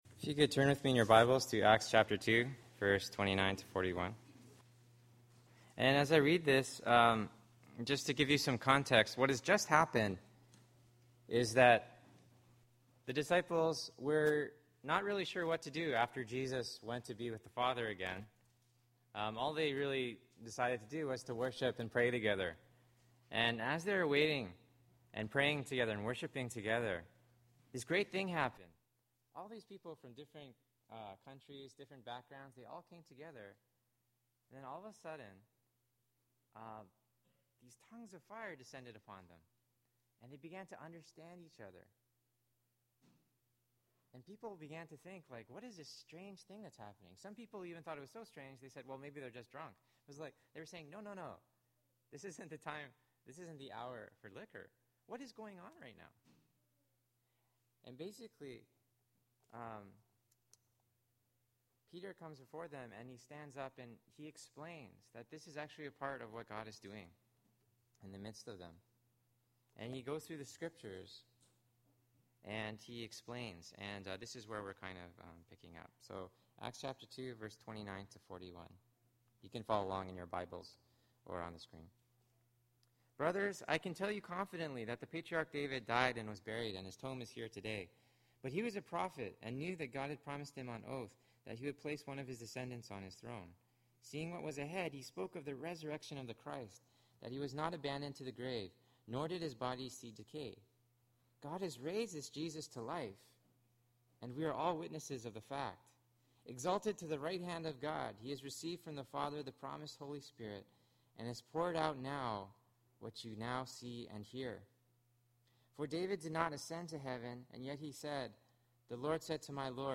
Christian Mission – Going Out to the World: October 11, 2009 Sermon